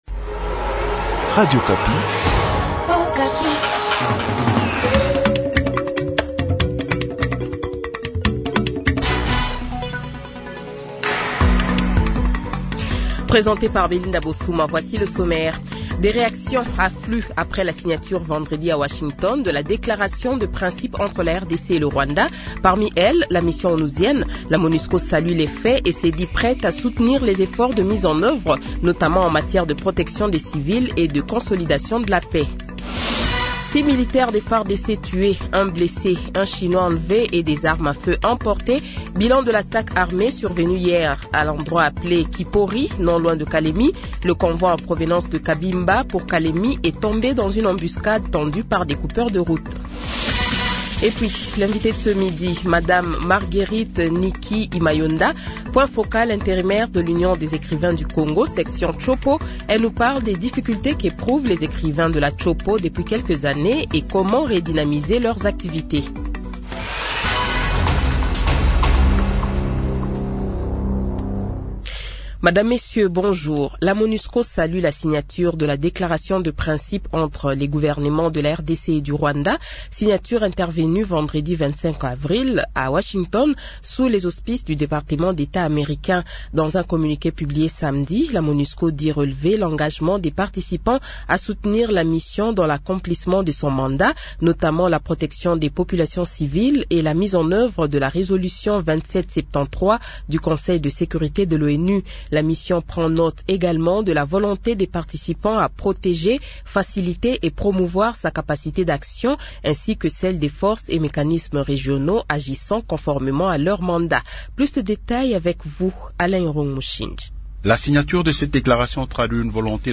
Journal Francais Midi
Le journal de 12h, 27 Avril 2025 :